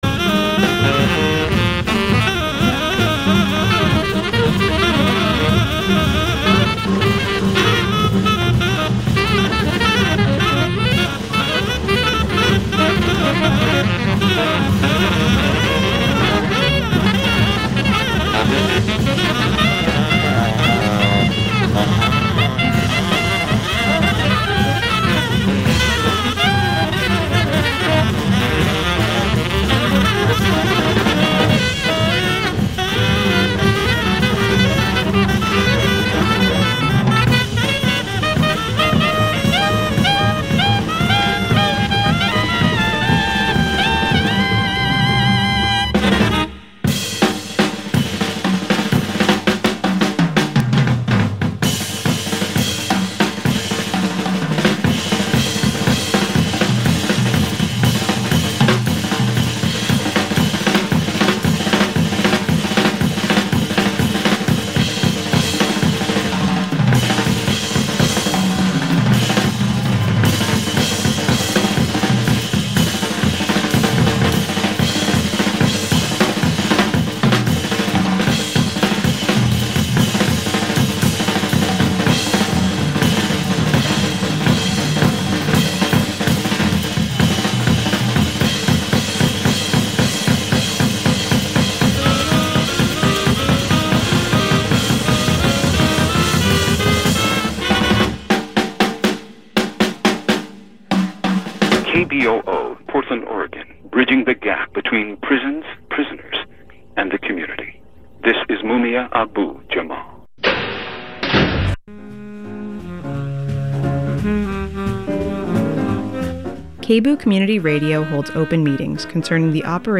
INVESTIGATIVE News Radio